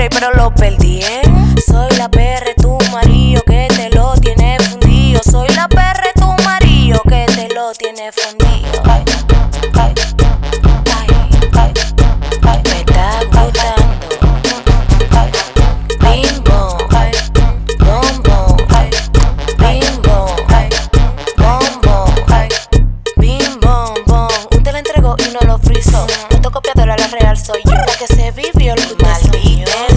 Жанр: R&b / Латиноамериканская музыка / Соул / Фанк